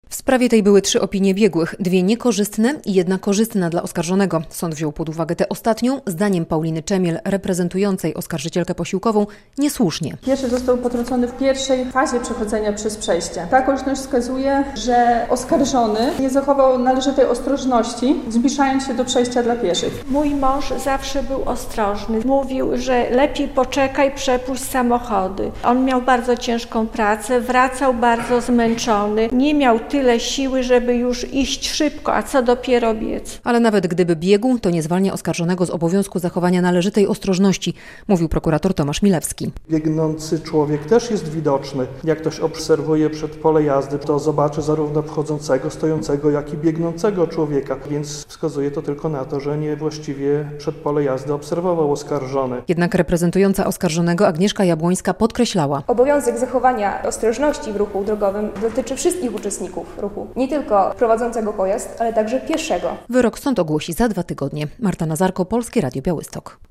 Przed białostockim sądem zakończył się proces apelacyjny 70-latka, który śmiertelnie potrącił na przejściu pieszego - relacja